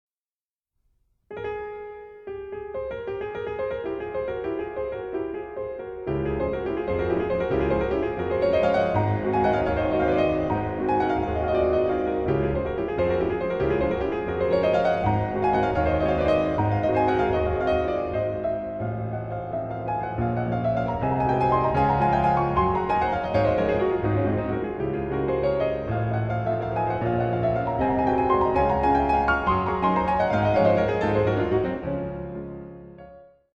２台ピアノ版
緻密繊細な詩的世界からユーモラスな表情まで、
ひとつひとつの表現が納得ゆくまで磨きあげられて響き合う。
三重県総合文化センター